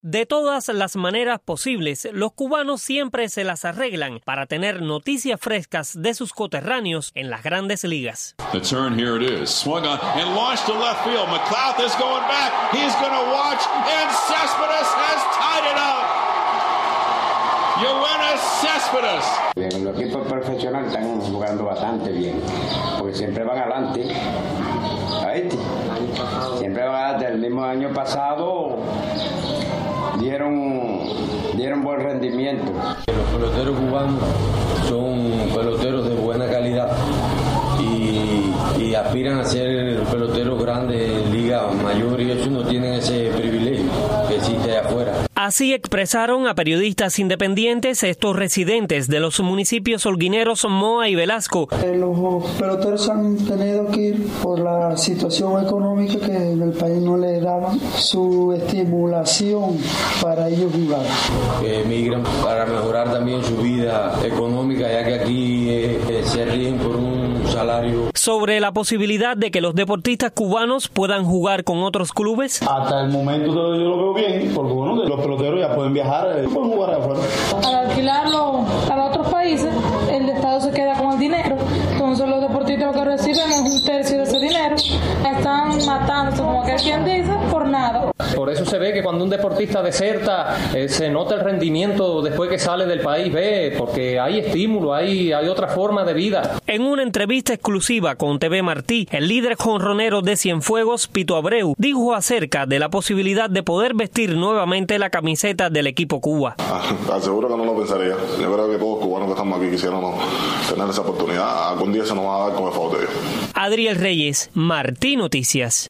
Por qué muchos peloteros cubanos han decidido hacer sus carreras en el béisbol profesional fuera de la isla? Periodistas independientes buscaron respuestas entre ciudadanos de Holguín